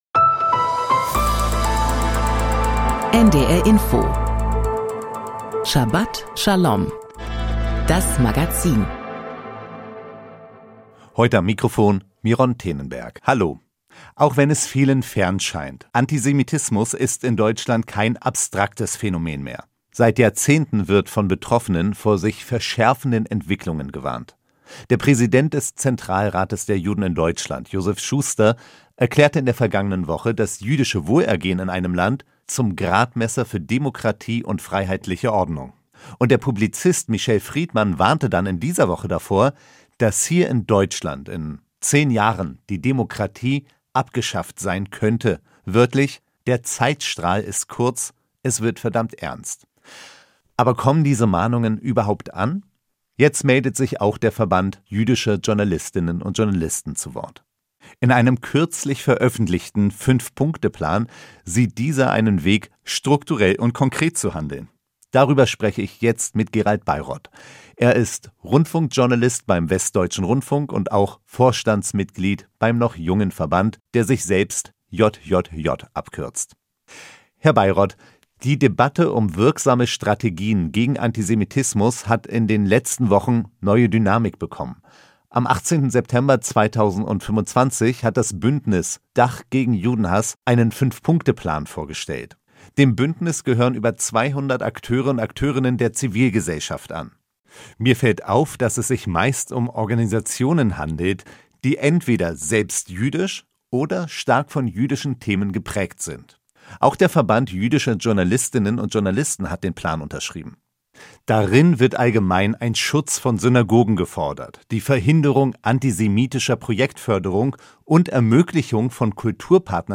Das Magazin Schabat Schaom berichtet aus dem jüdischen Leben mit Nachrichten, Interviews, Berichten und Kommentaren.